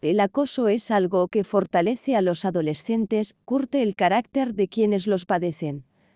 Creado por IA con CANVA